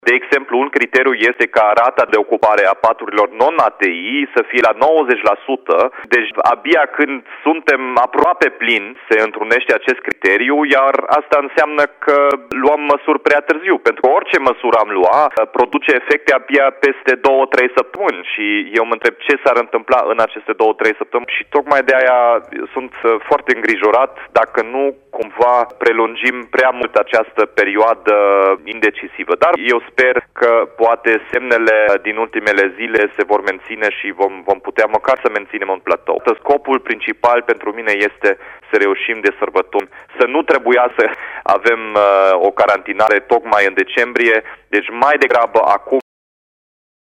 Edilul a subliniat, la Radio Timișoara, că o astfel de decizie este luată de autoritățile centrale, în baza unor criterii, care în opinia lui Dominic Fritz ar trebui modificate.